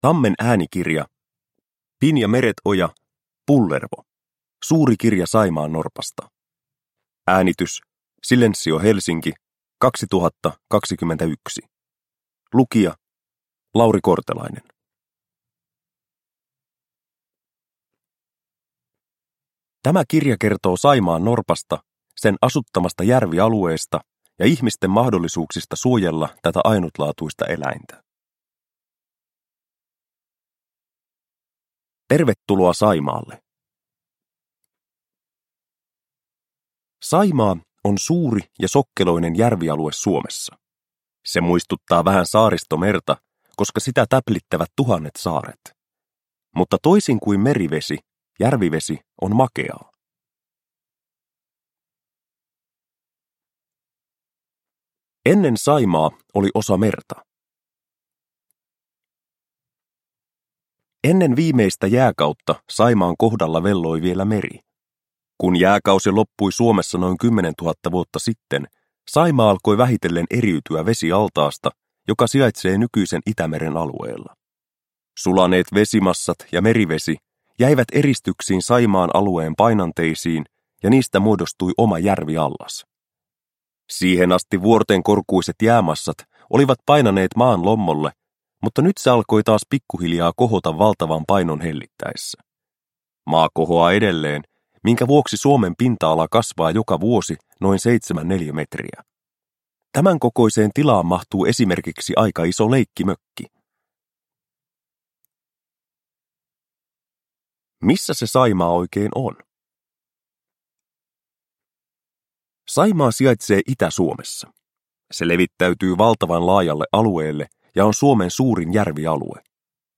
Pullervo – Ljudbok – Laddas ner